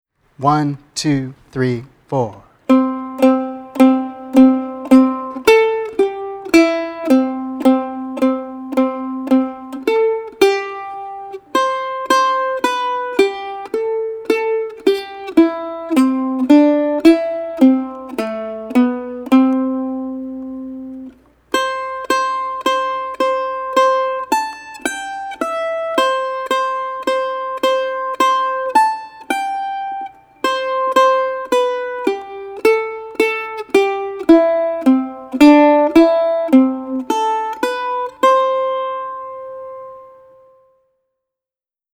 Voicing: Mandolin